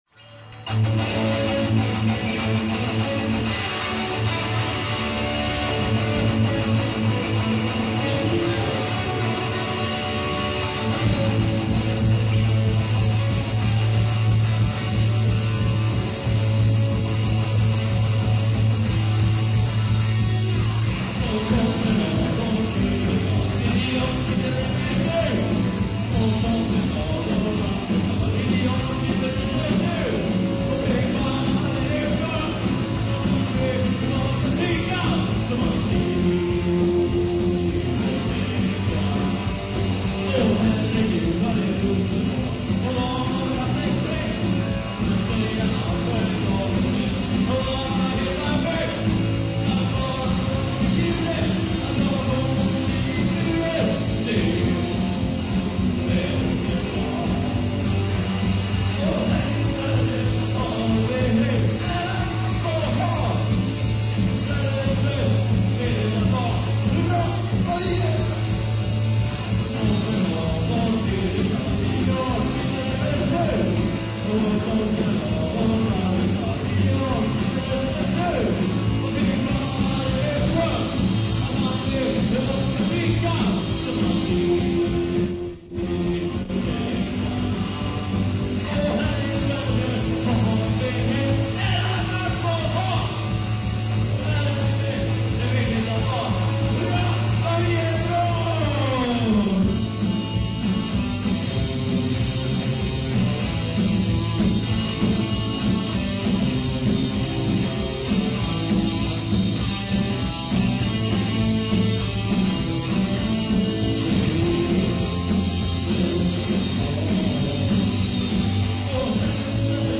Guitar
Drums
Bass
Voice